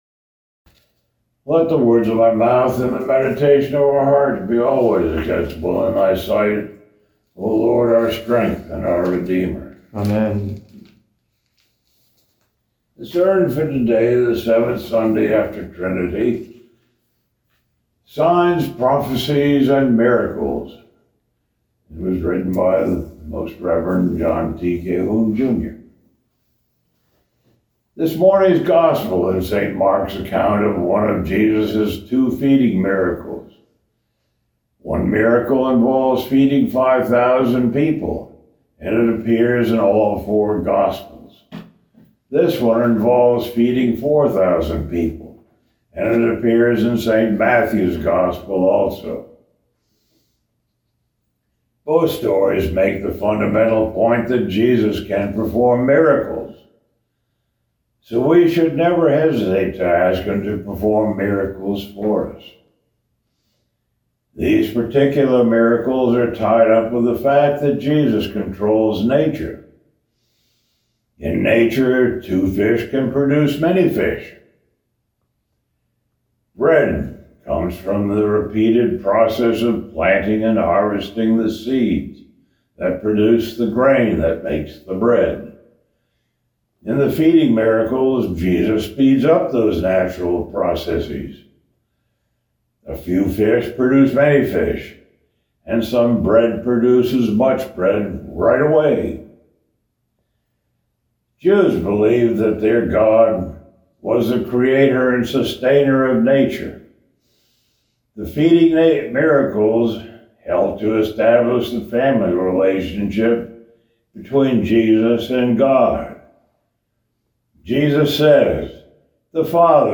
Morning Prayer - Lay Reader Service
Sermon Thought: